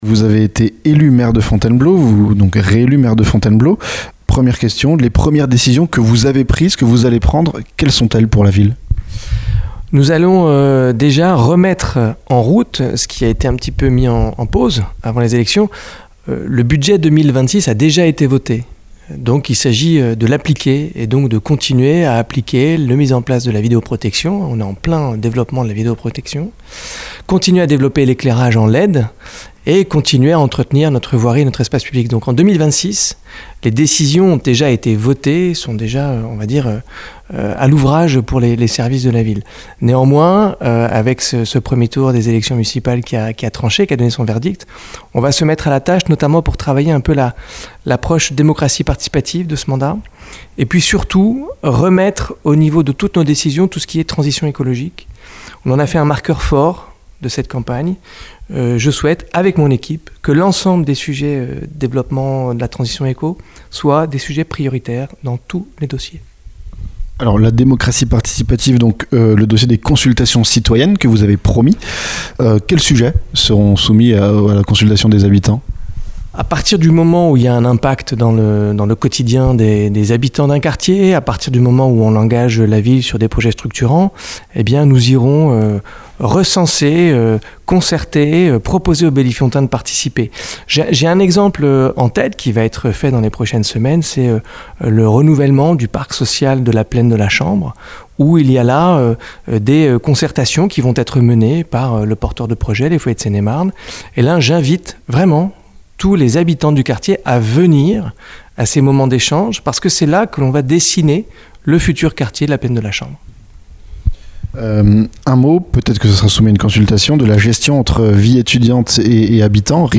FONTAINEBLEAU - Entretien avec Julien Gondard, réélu maire